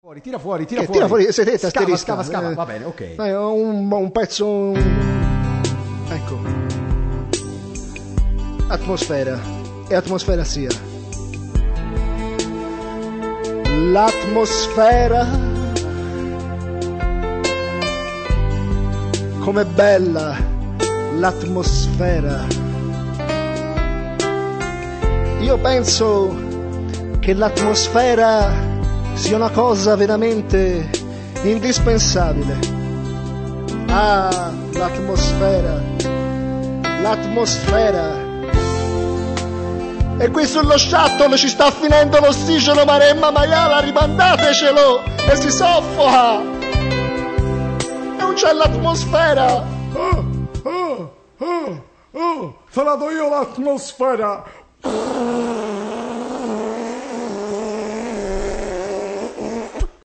Candele, trasparenze, musiche soffuse... Un brano di atmosfera, improvvisato